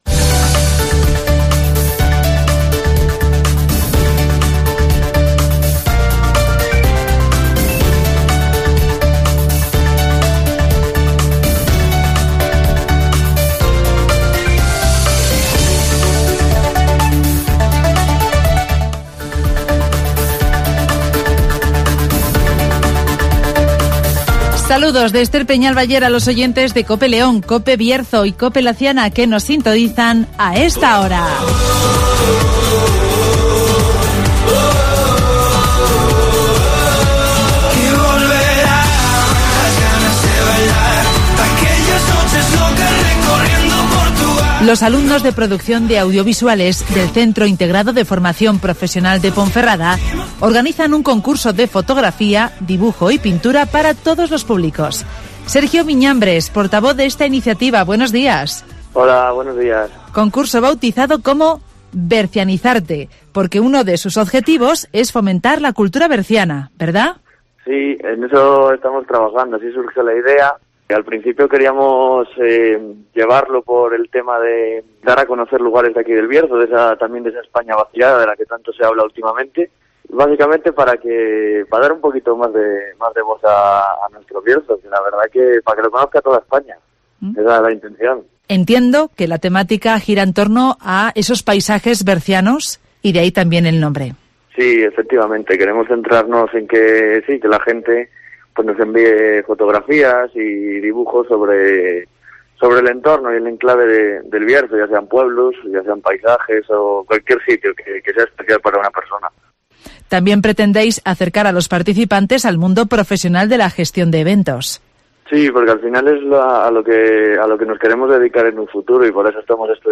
Los alumnos del Cifp de Ponferrada organizan el certamen de fotografía, dibujo y pintura Bercianizarte (Entrevista